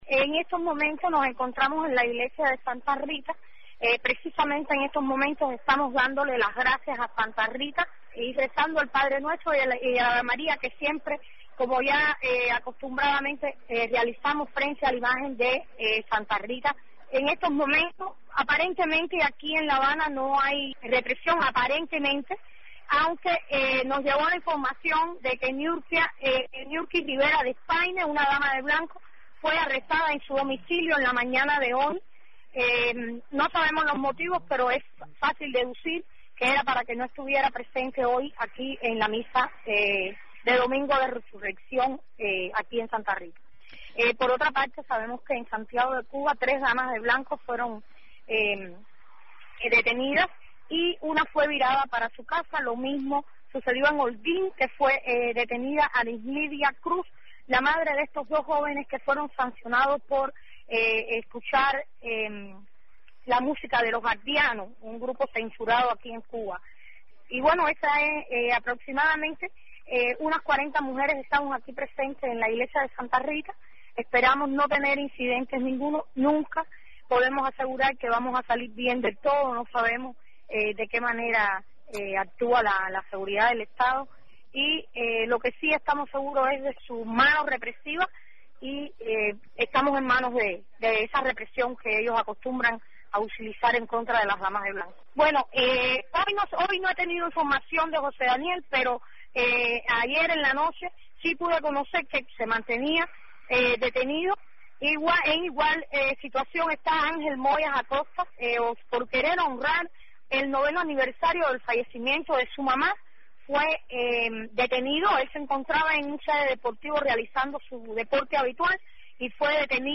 Testimonio de la Dama de Blanco